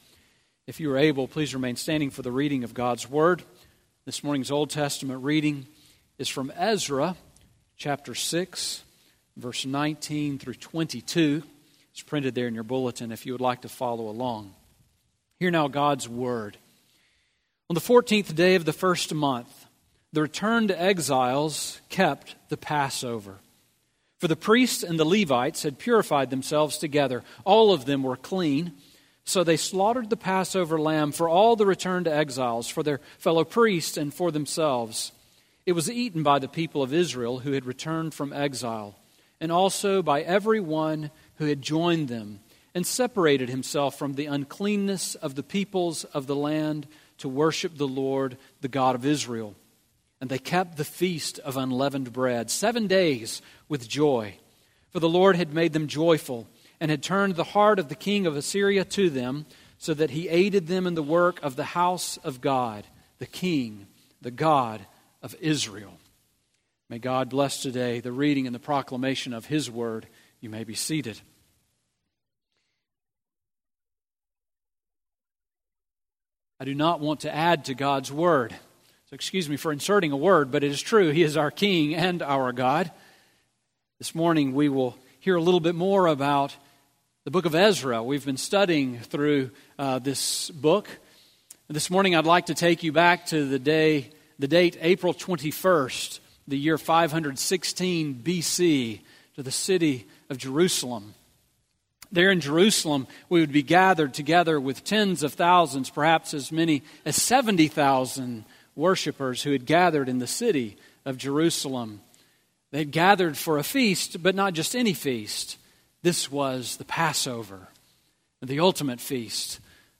Sermon on Ezra 6:19-22 from July 16